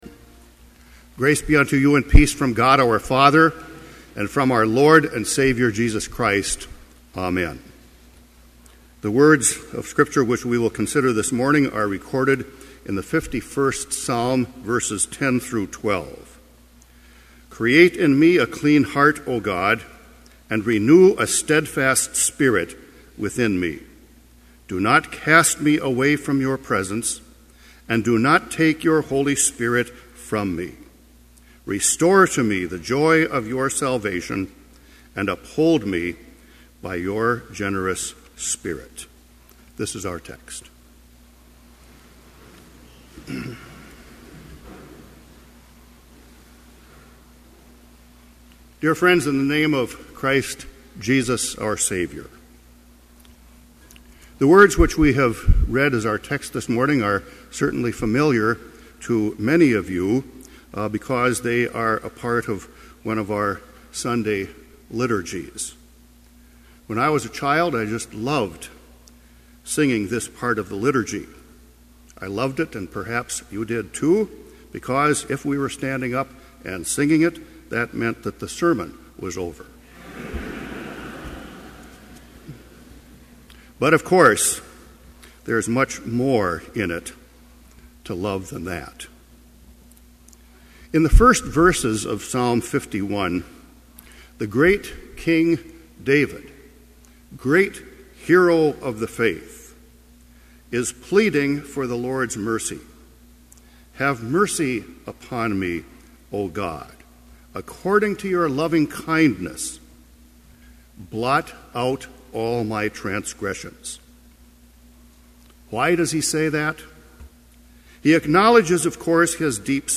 Chapel worship service held on August 30, 2011, BLC Trinity Chapel, Mankato, Minnesota
Complete service audio for Chapel - August 30, 2011